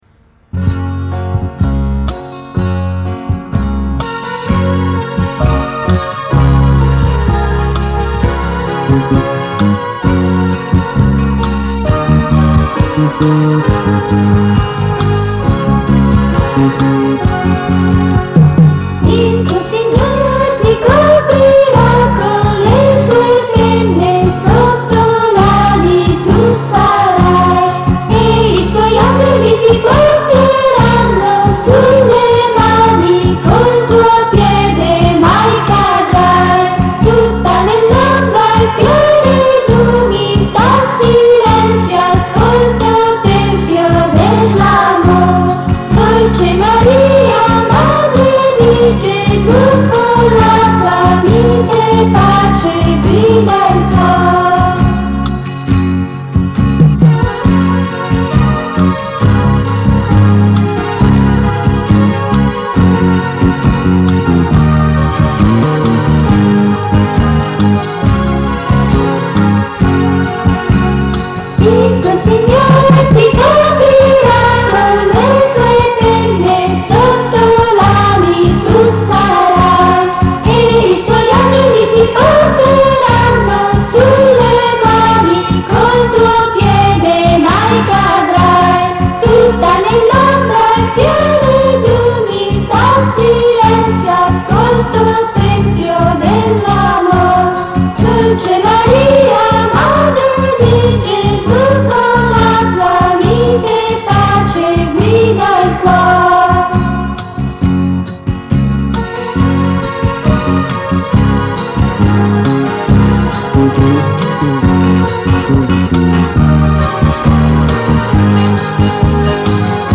Canto